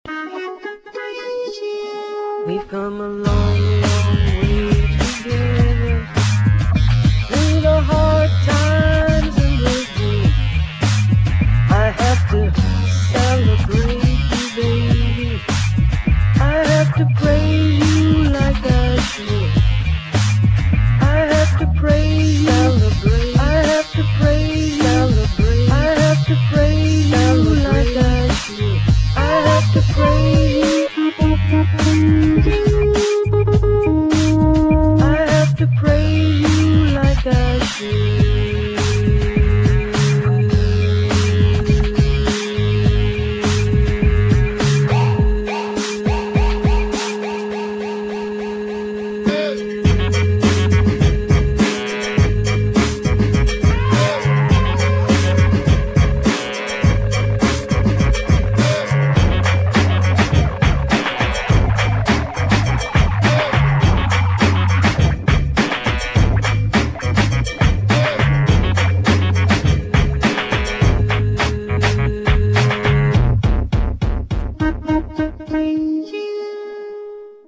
[ BIG BEAT | ELECTRO ]